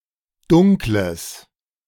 Dunkel (German: [ˌdʊŋkl̩] ), or Dunkles (German: [ˈdʊŋkləs]